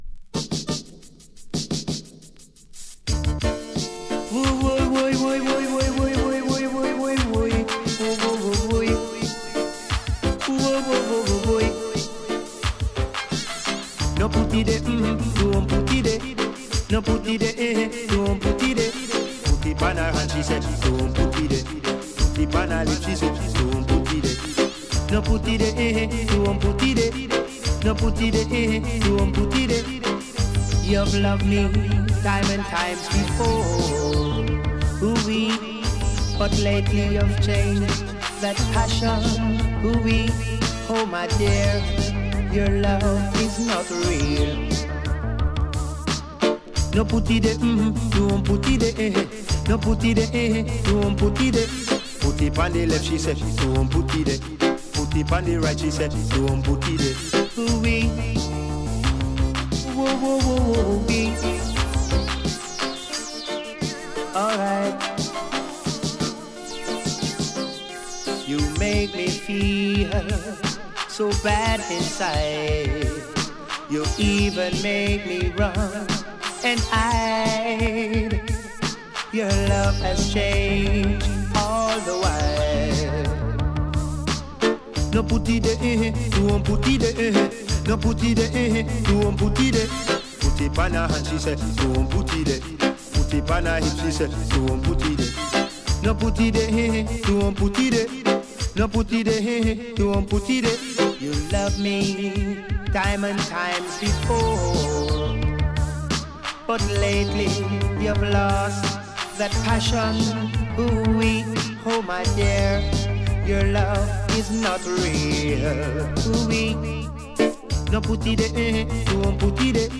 a fat and serious Digital Reggae session
let the bass drop
Vinyls only!